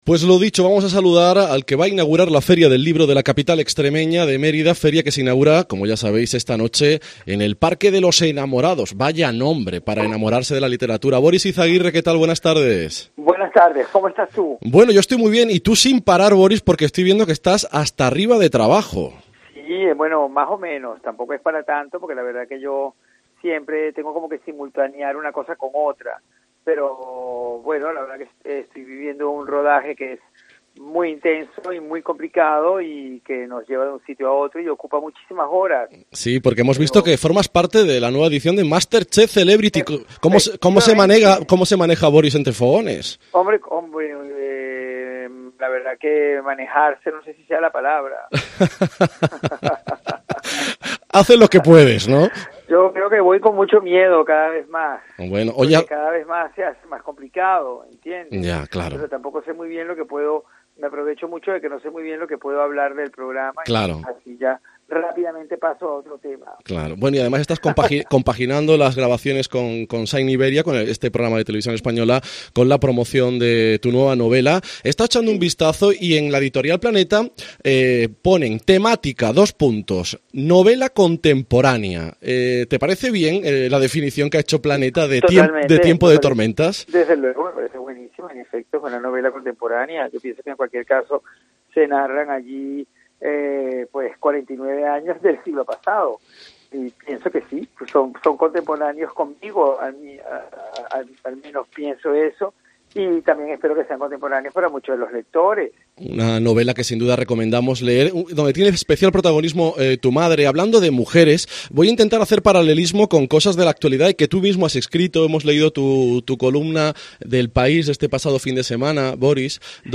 Entrevista a Boris Izaguirre en COPE Extremadura